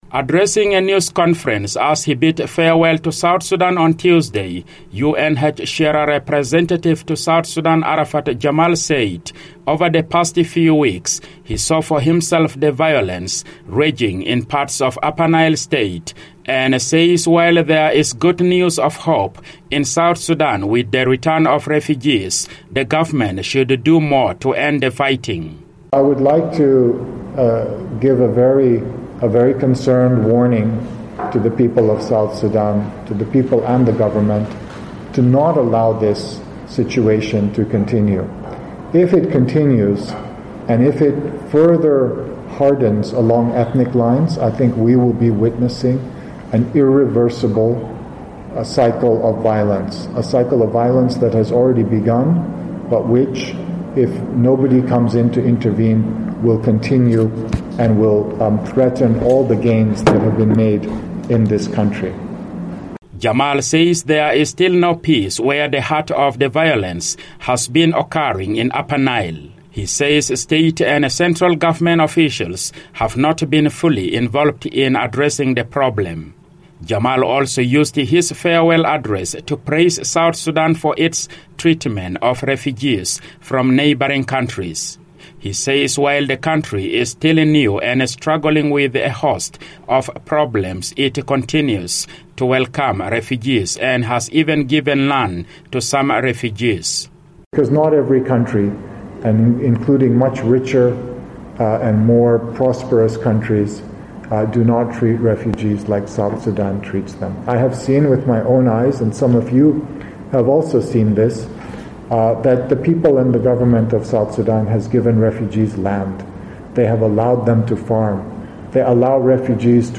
reports from Juba.